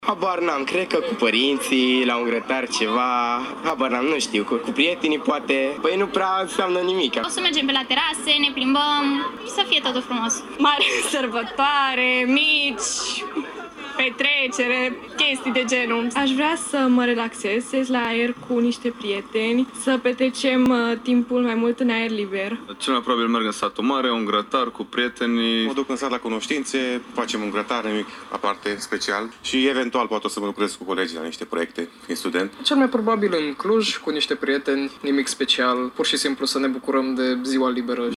Tinerii însă spun că este doar o zi liberă în care se pot bucura de prieteni şi familie: